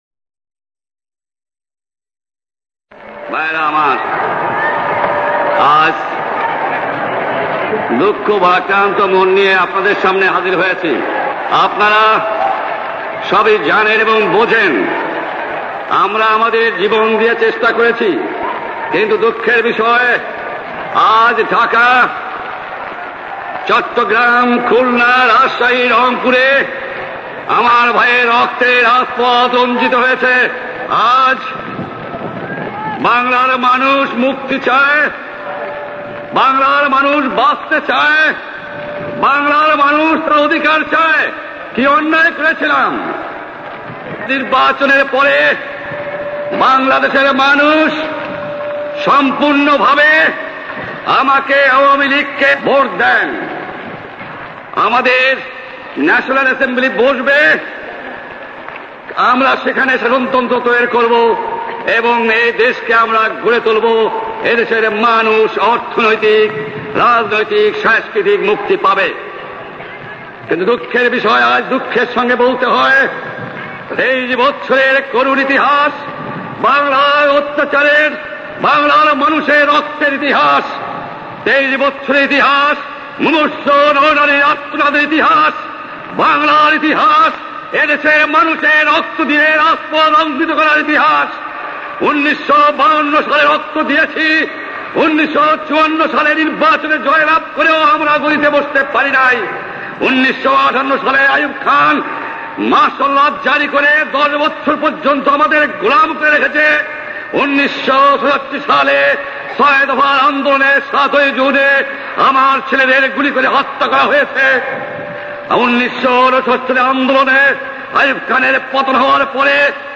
Sheikh Mujib 7th March 1971 Speech.mp3